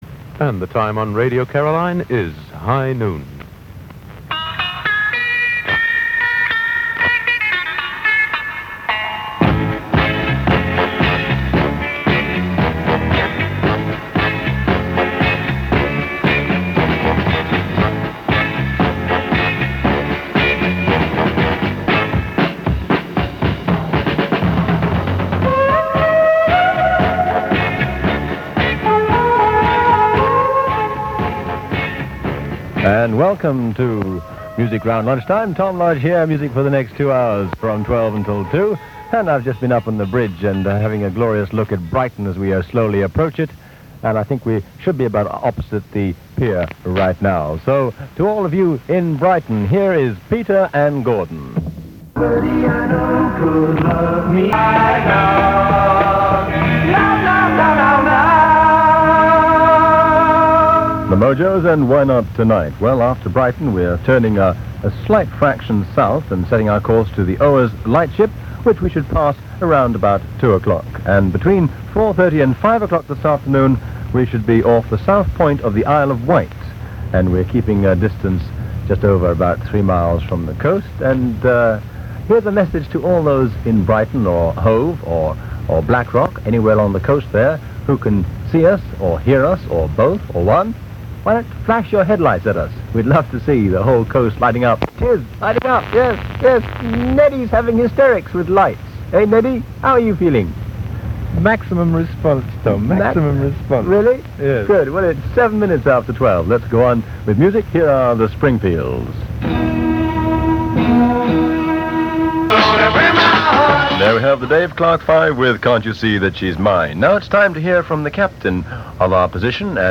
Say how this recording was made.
MV Caroline sailing past Brighton, en route to the Isle of Man